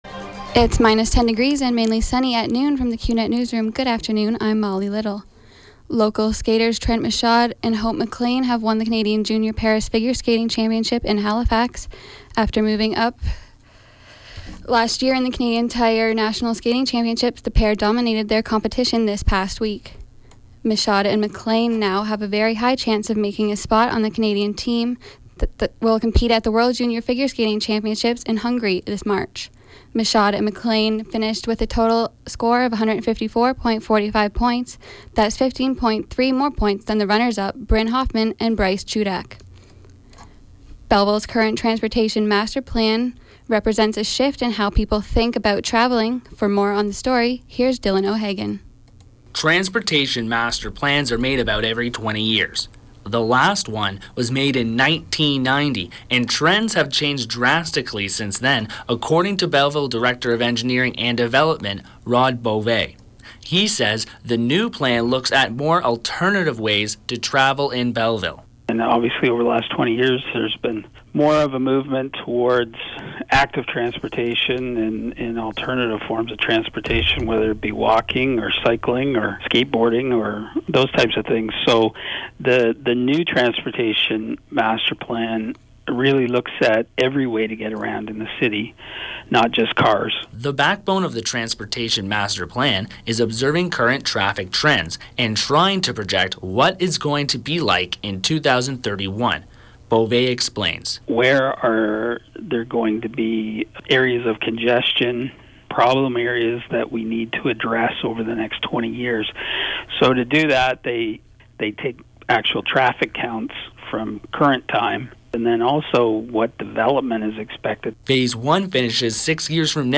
91X newscast – Wednesday, Jan. 21, 2016 – 12 p.m.